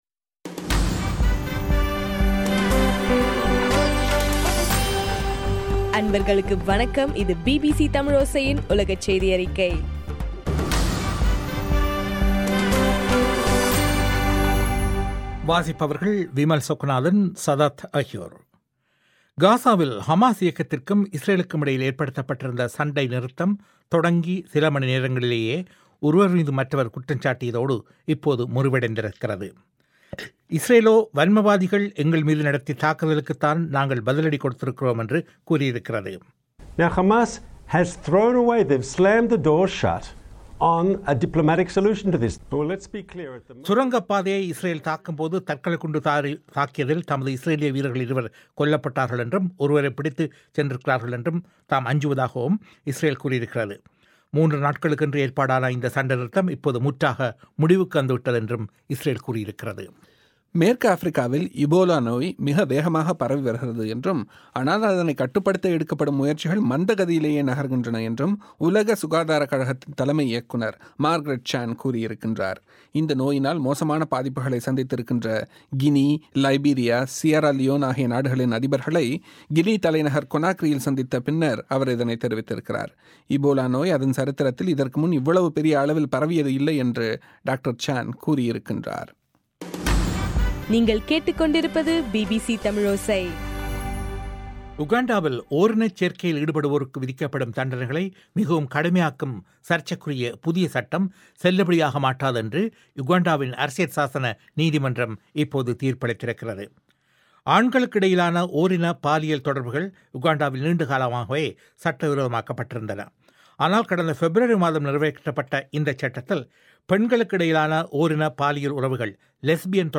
ஆகஸ்ட் 1 2014 பிபிசி தமிழோசையின் உலகச் செய்திகள்